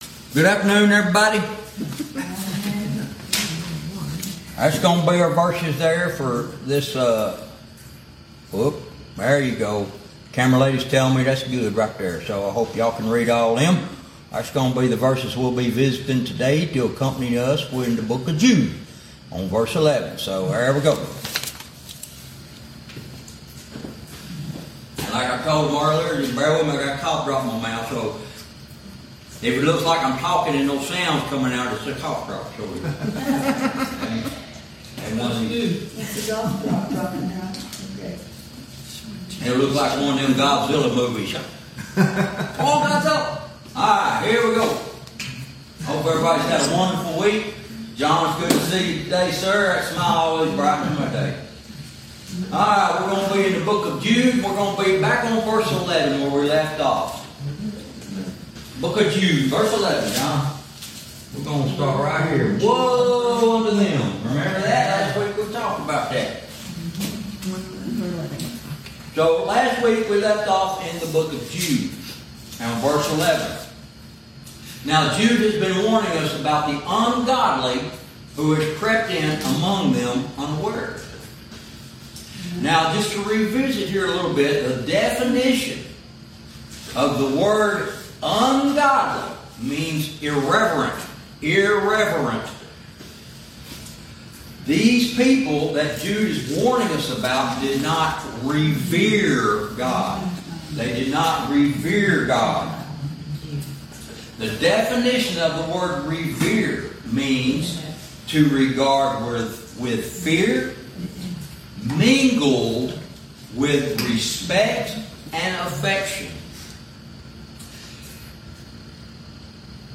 Verse by verse teaching - Lesson 38